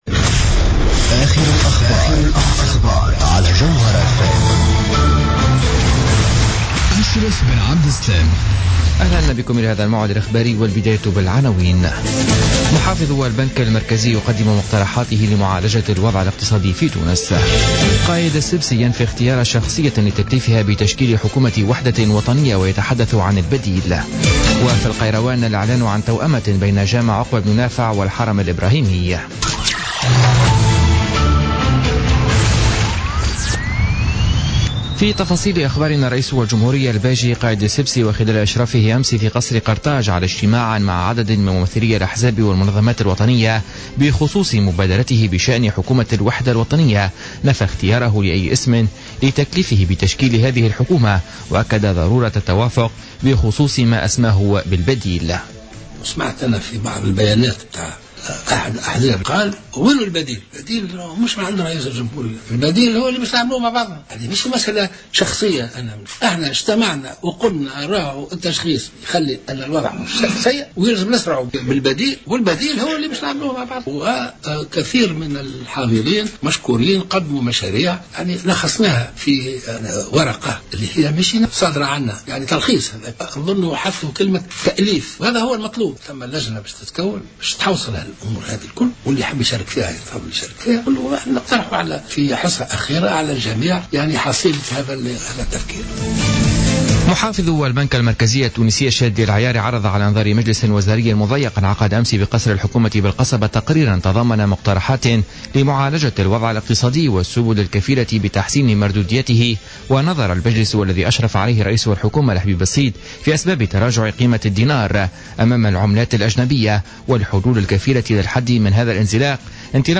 نشرة أخبار منتصف الليل ليوم الخميس 23 جوان 2016